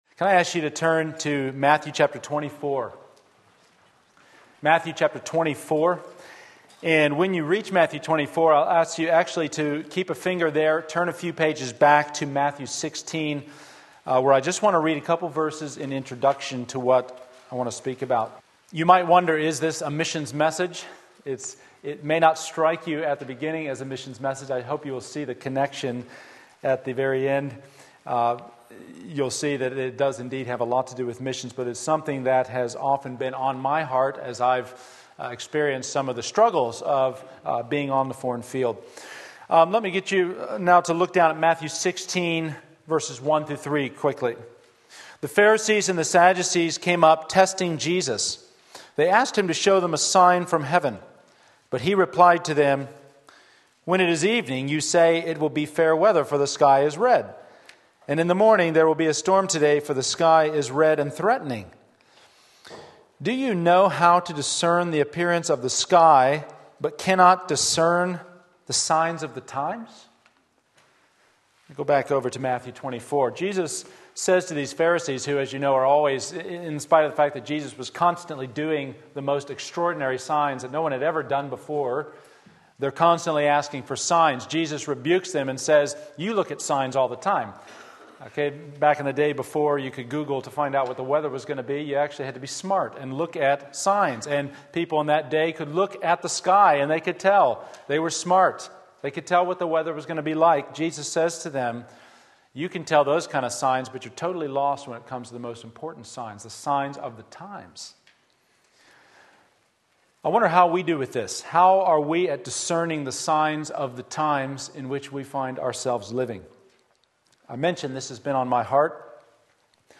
Sermon Link
Understanding the Times Matthew 24:1-14 Sunday Morning Service